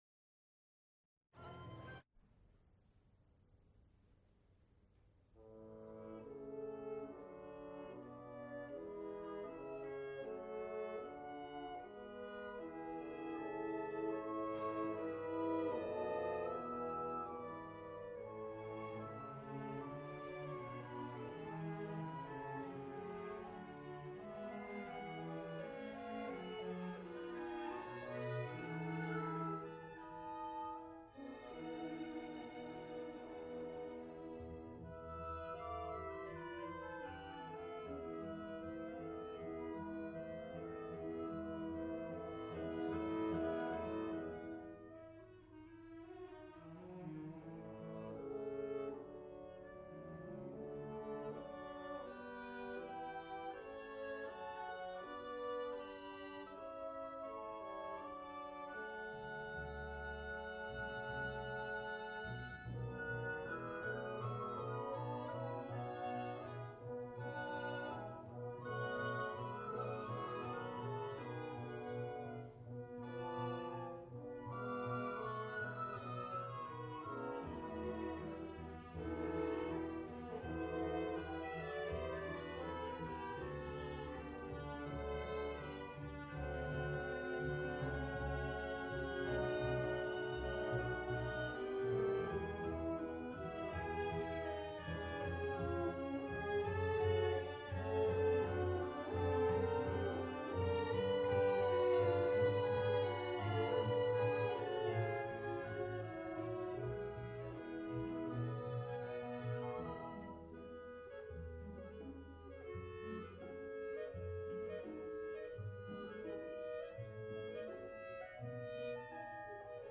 Suites (Orchestra), Symphonies, Orchestral music
Recorded live March 7, 1978, Schenley Hall, University of Pittsburgh.